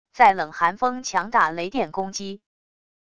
在冷寒风强大雷电攻击wav音频